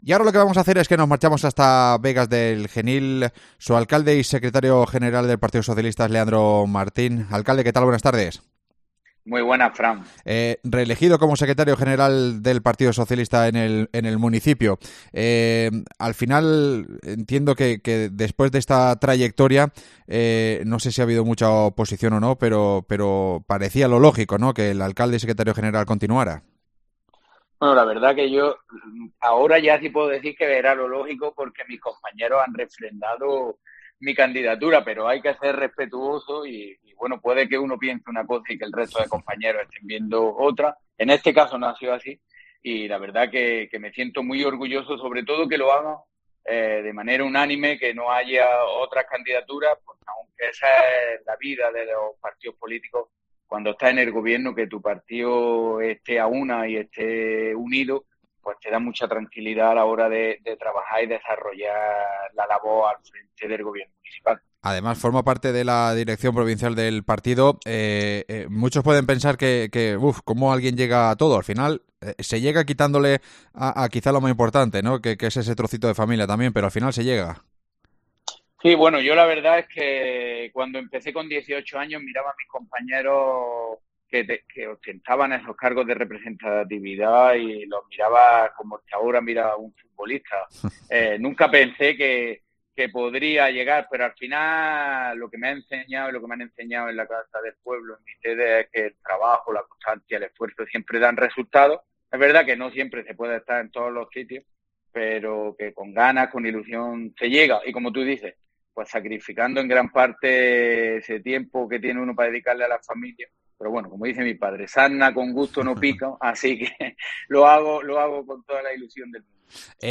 AUDIO: Los cuenta su alcalde y secretario general del PSOE del municipio, Leandro Martín